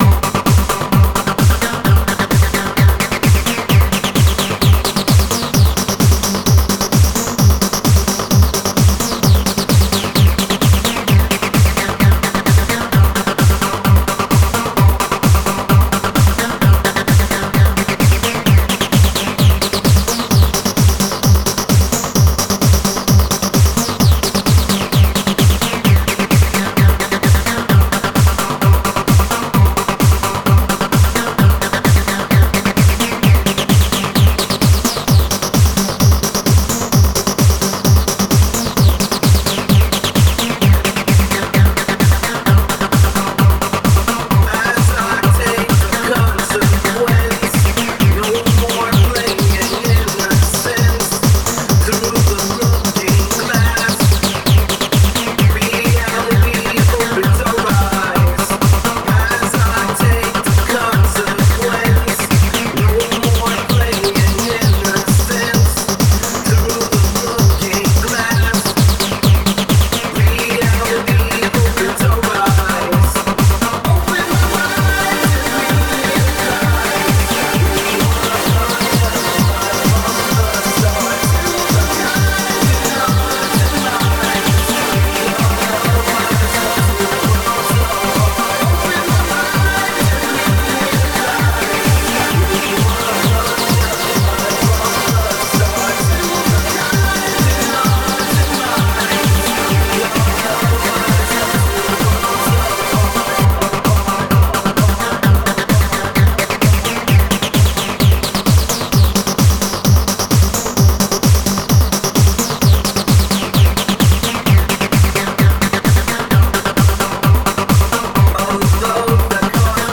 EBM and Industrial net radio show